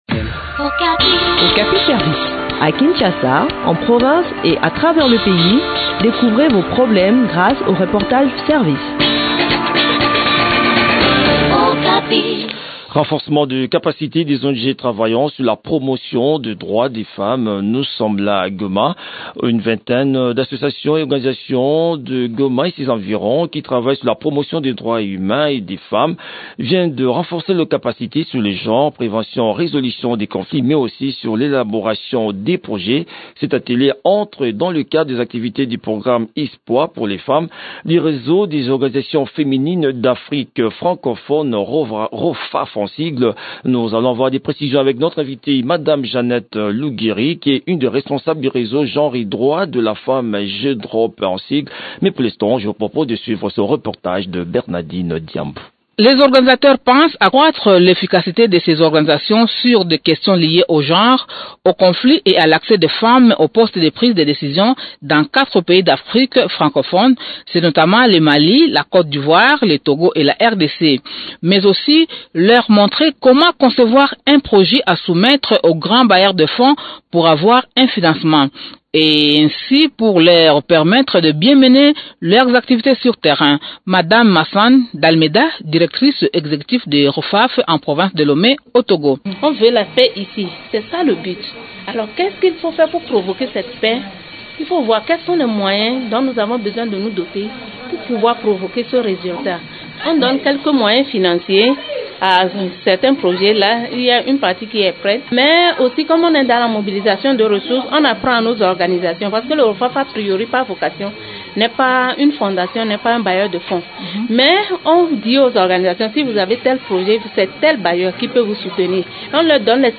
Le point sur l’organisation de cette session de formation dans cette interview